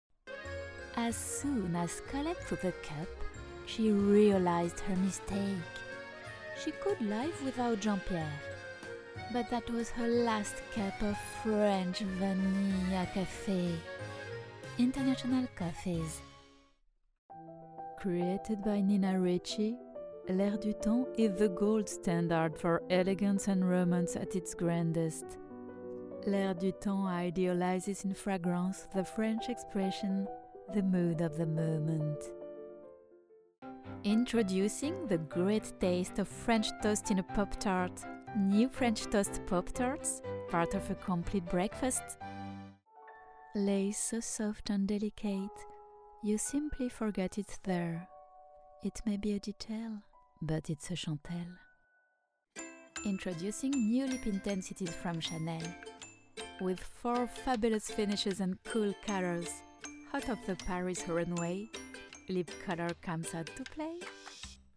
Female
Conversational, Cool, Energetic, Friendly, Funny, Natural, Reassuring, Smooth, Soft, Versatile, Warm, Young
Parisian (native)
I'm a French Parisian Voice talent and a trained actress.
Educationnal.mp3
Microphone: Audiotechnica 4040